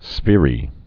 (sfîrē)